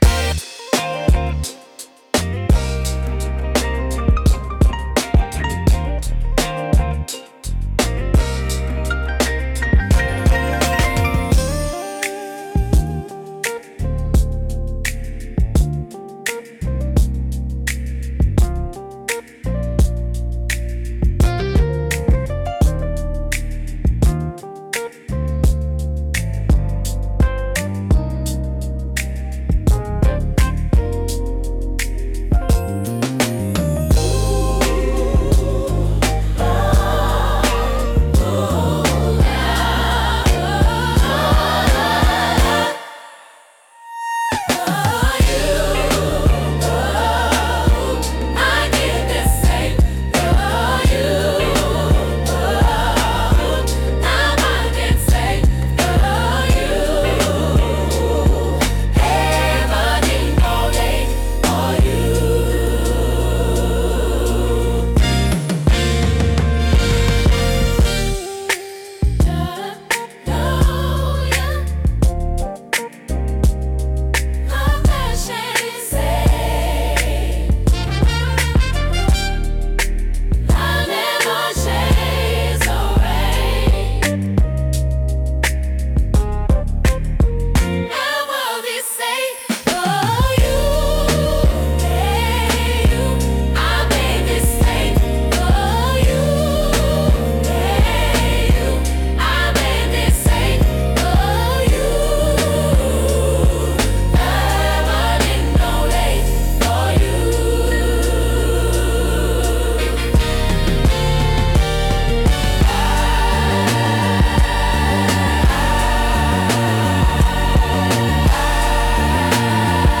リズムの重厚感とグルーヴ感、感情豊かなボーカルが特徴で、深みと熱量のあるサウンドが魅力です。